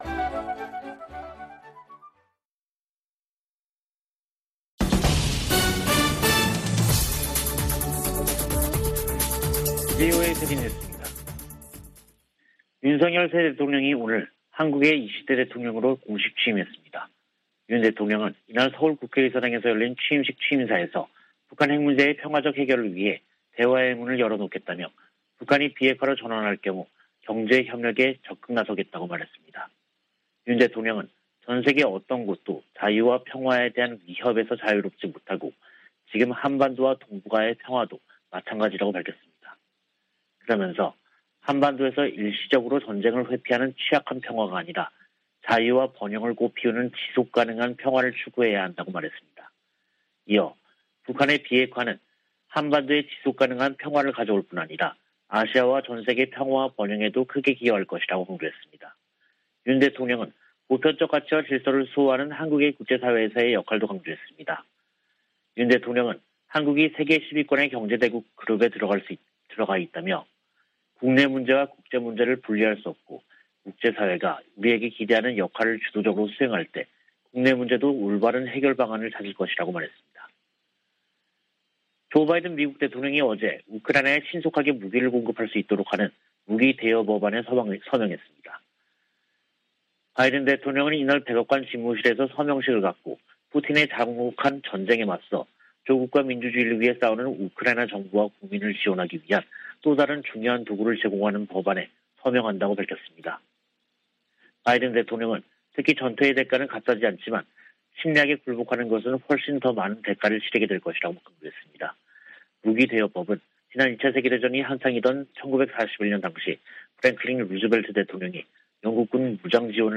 VOA 한국어 간판 뉴스 프로그램 '뉴스 투데이', 2022년 5월 10일 3부 방송입니다. 윤석열 한국 대통령이 10일 취임했습니다. 윤 대통령은 비핵화로 전환하면 북한 경제를 획기적으로 개선할 계획을 준비하겠다고 밝혔습니다.